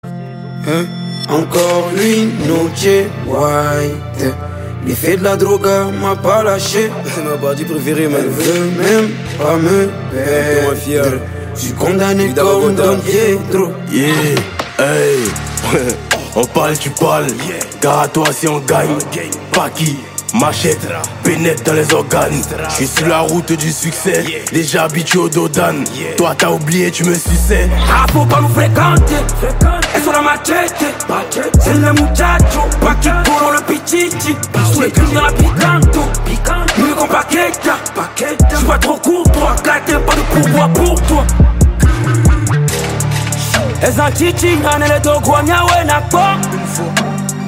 Catégorie Rap